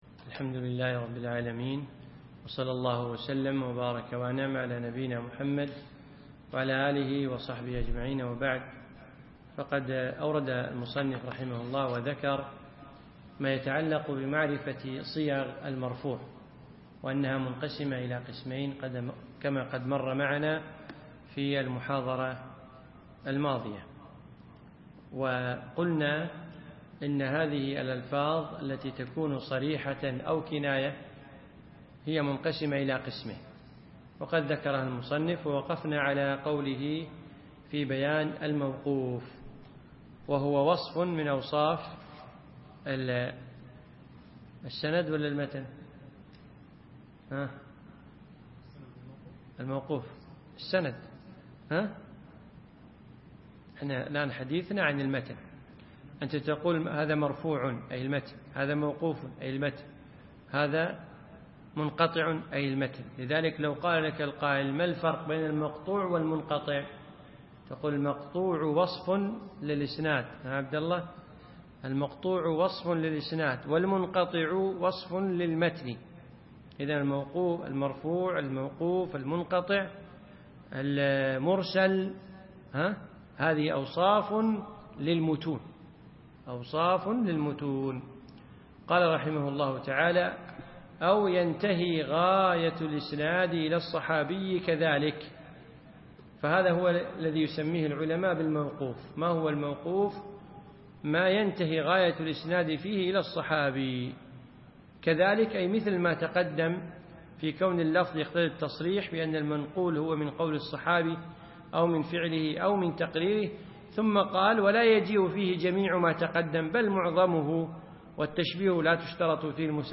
الدرس السادس عشر